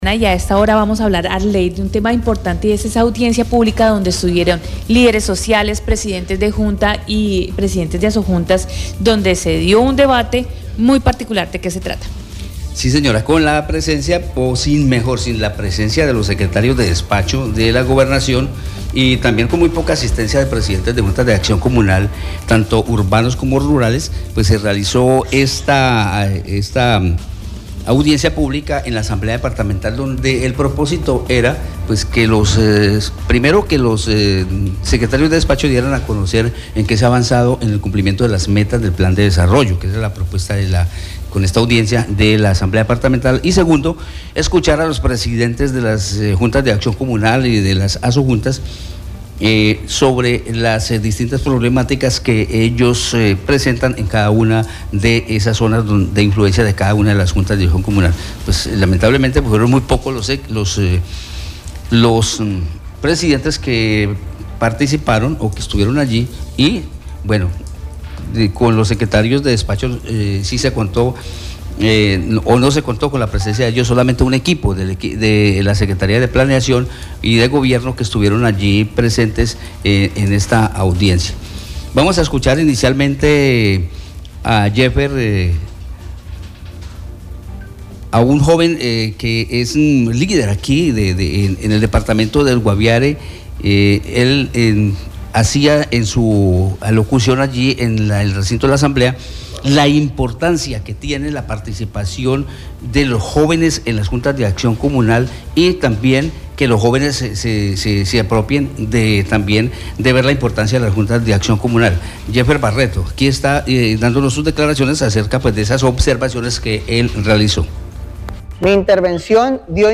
Sin la presencia de los secretarios de despacho de la Gobernación y con una muy poca presencia de presidentes de Juntas de Acción Comunal, se cumplió este miércoles la audiencia pública en la asamblea departamental en la que se pretendía conocer avances en el cumplimiento de las metas del Plan de Desarrollo del departamento.
Por otro lado, el diputado William Ramírez, presidente de la Asamblea del Guaviare, manifestó su preocupación por la ausencia en el recinto de los secretarios de despacho y la poca presencia de los presidentes y líderes comunales de quienes se esperaba una amplia asistencia y participación.